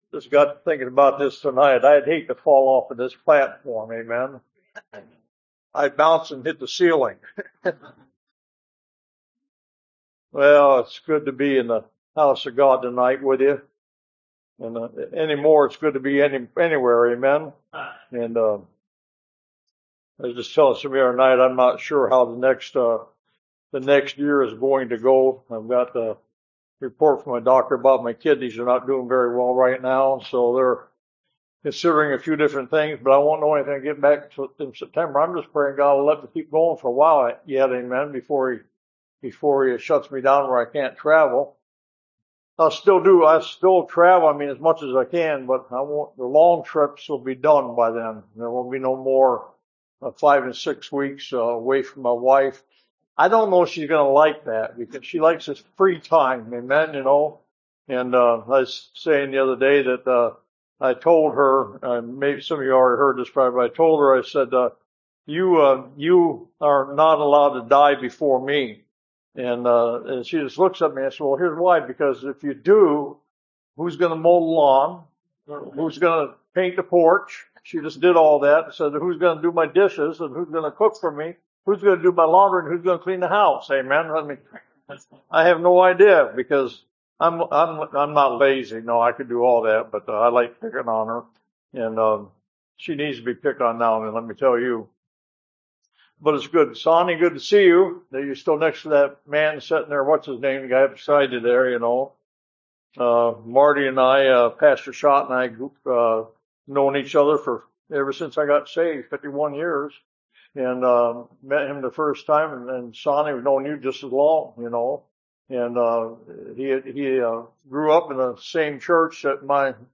Revival Preachings Service Type: Revival